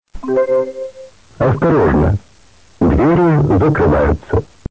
Скажу сразу, что интонации всё-таки заставили его изменить на более энергичные, чем было прежде. Голос узнаётся, но окраска вся пропала.
Несильно зажат динамический диапазон, но очень сильно заужена полоса - плоский звук как из динамика мобильника.